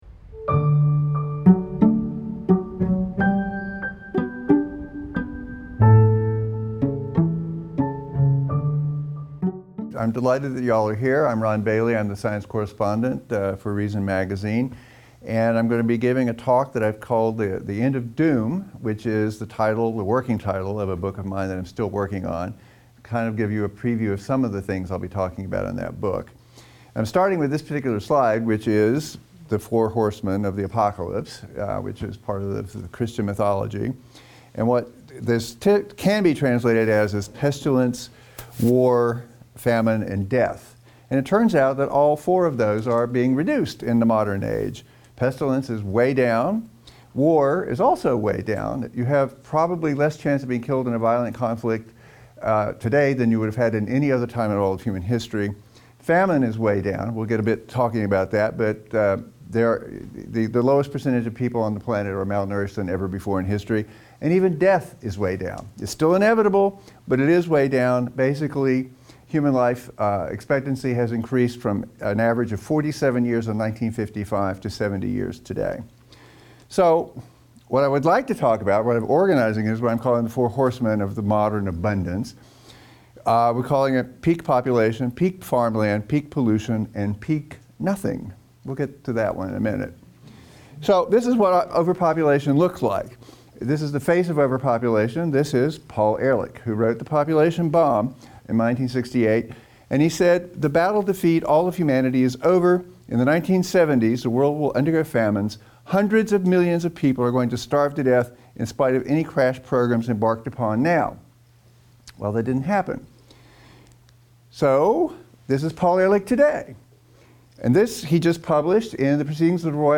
In a talk in front of a live, in-studio audience at Reason's LA headquarters